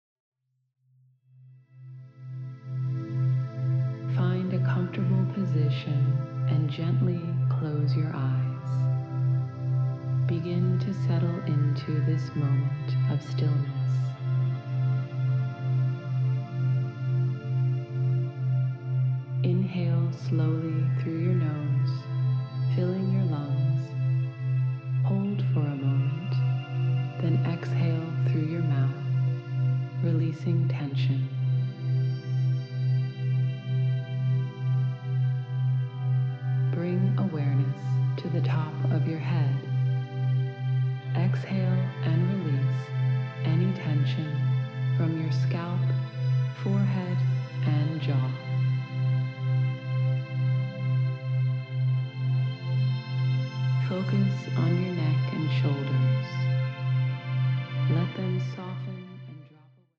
Preview Delta in C